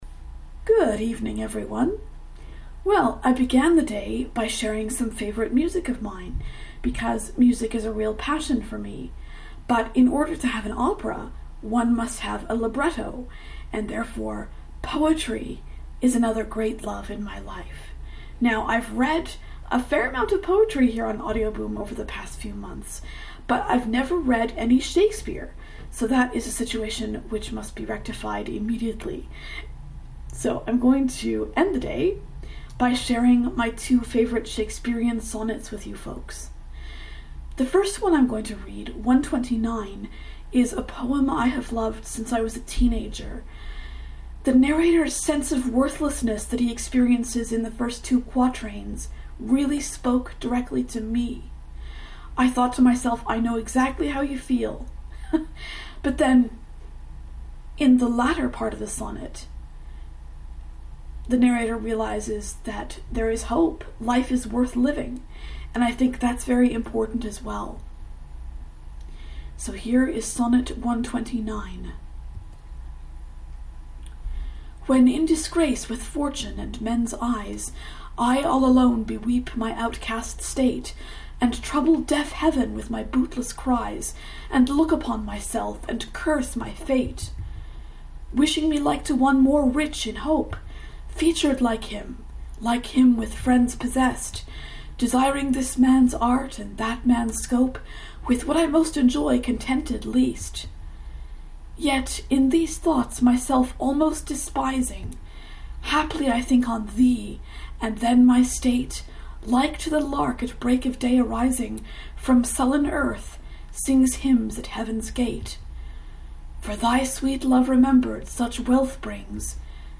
Well, I realized tonight that in all the poetry readings I've done here on AudioBoom, I've never read any Shakespeare! So I am rectifying that situation here and now.